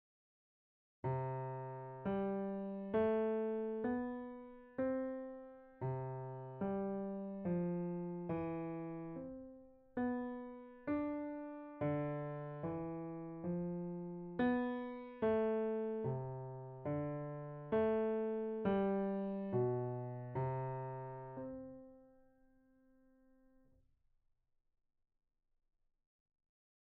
Exercise 6 – Piano
Pozzoli_1_Example6_piano.mp3